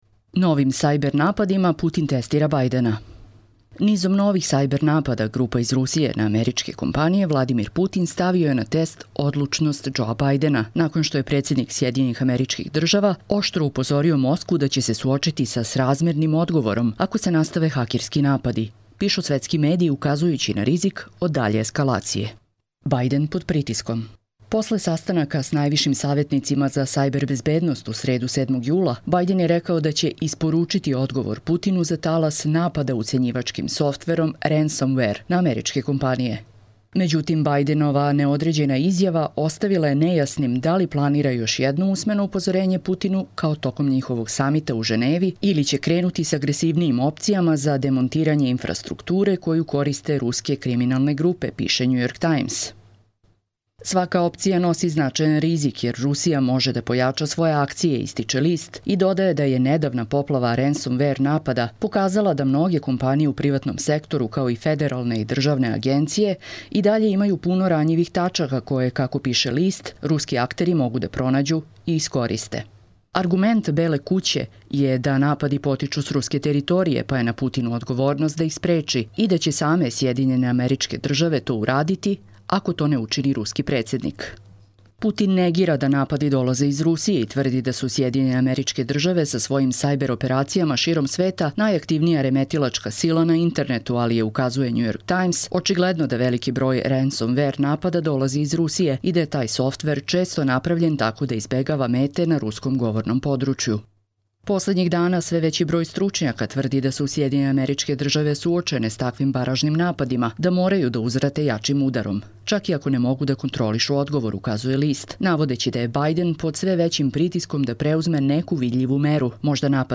Čitamo vam: Novim sajber napadima Putin testira Bidena